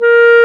Index of /90_sSampleCDs/Roland L-CDX-03 Disk 1/CMB_Wind Sects 1/CMB_Wind Sect 1
WND CLAR A#4.wav